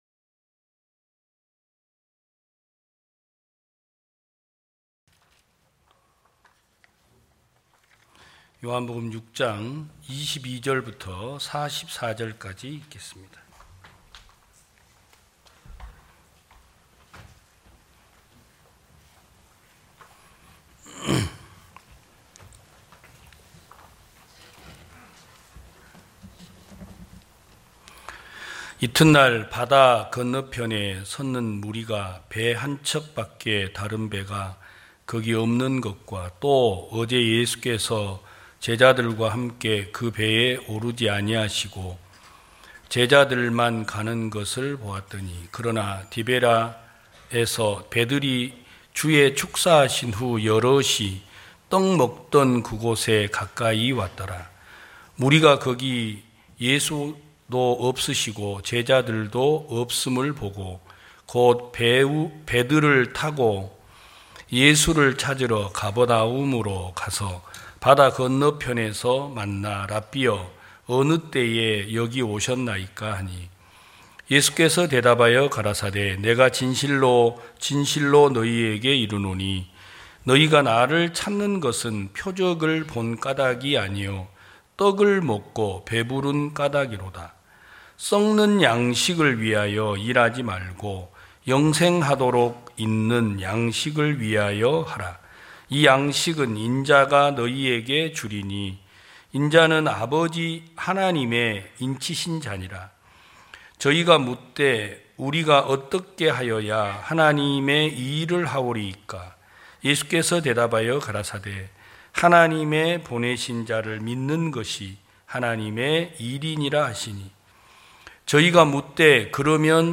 2022년 07월 31일 기쁜소식부산대연교회 주일오전예배
성도들이 모두 교회에 모여 말씀을 듣는 주일 예배의 설교는, 한 주간 우리 마음을 채웠던 생각을 내려두고 하나님의 말씀으로 가득 채우는 시간입니다.